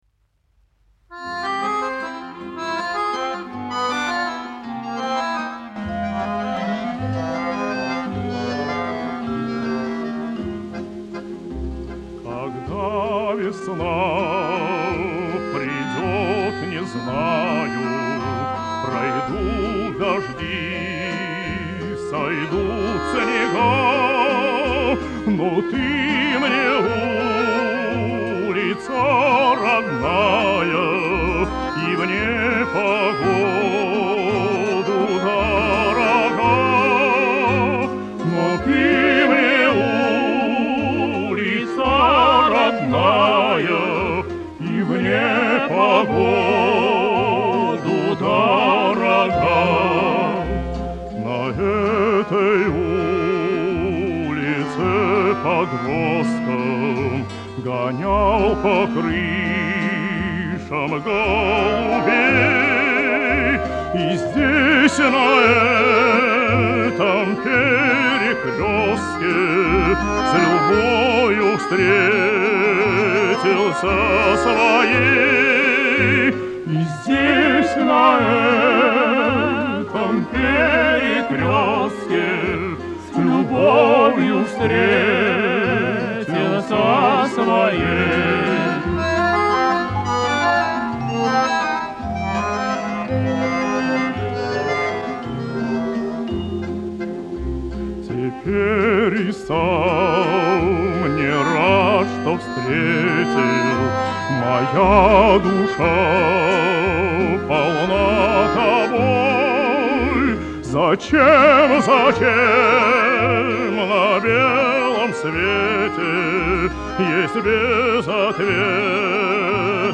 "Длинный" вариант без прерываний.